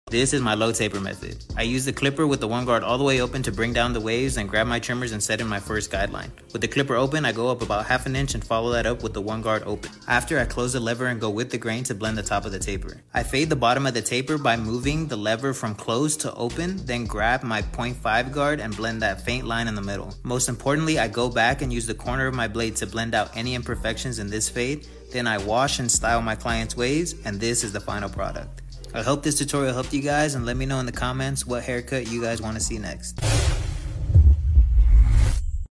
DIY Tutorial 💈📚 This Barber demonstrates precise techniques to achieve a clean and stylish look.